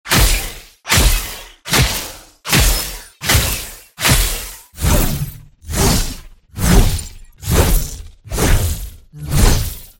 Fortnite | Code Cutter Pickaxe sound effects free download
Fortnite | Code Cutter Pickaxe Sounds!